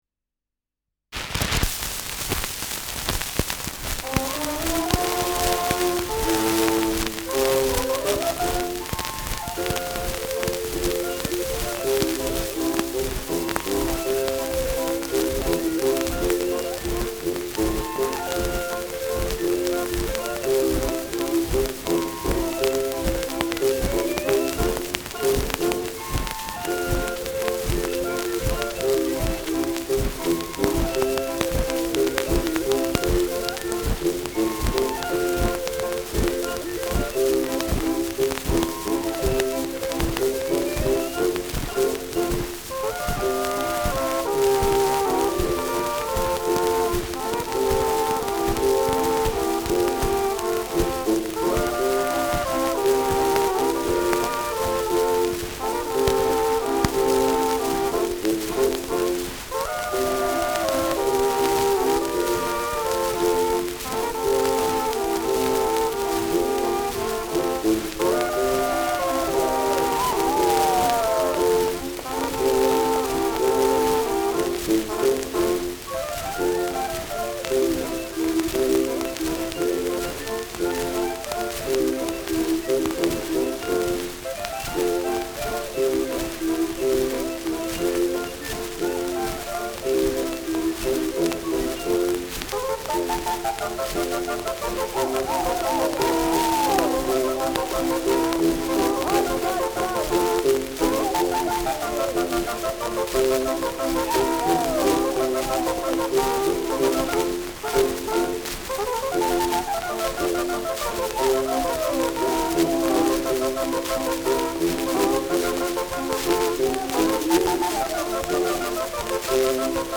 Schellackplatte
Stark verrauscht : Gelegentlich stärkeres Knacken : Durchgehend rumpelndes Nadelgeräusch
Zonophone-Orchester (Interpretation)
[Wien] (Aufnahmeort)